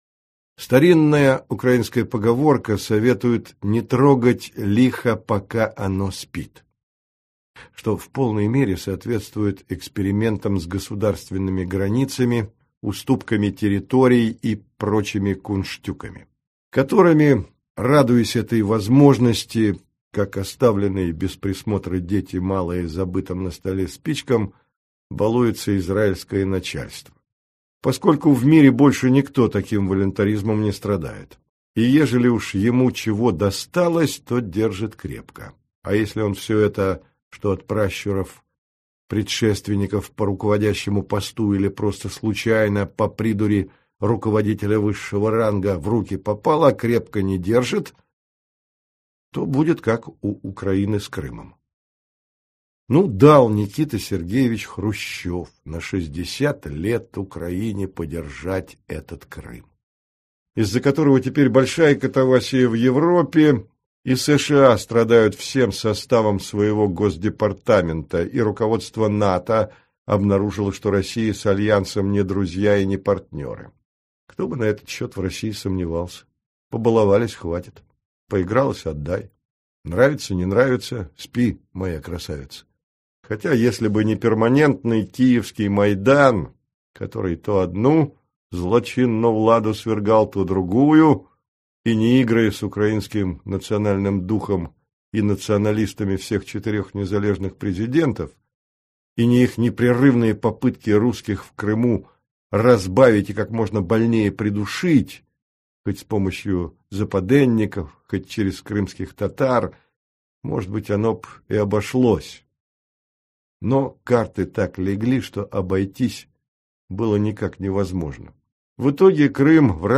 Аудиокнига Книга Израиля. Путевые заметки о стране святых, десантников и террористов | Библиотека аудиокниг